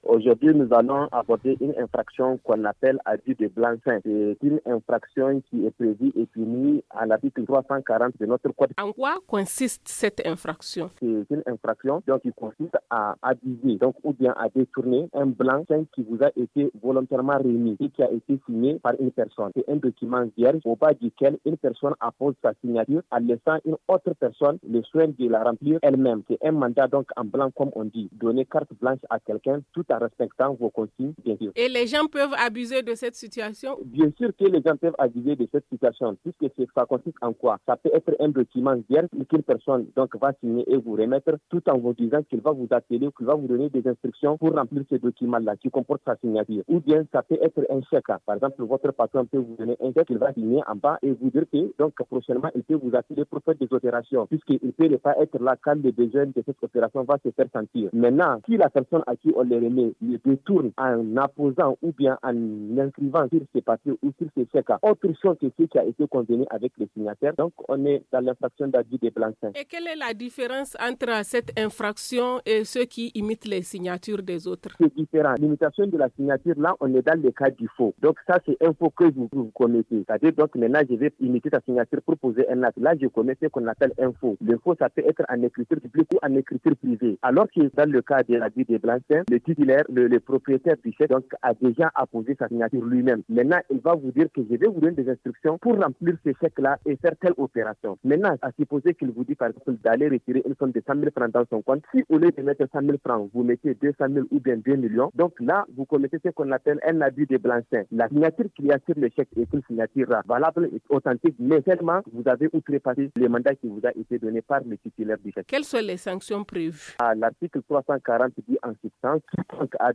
joint au téléphone
Le magazine en français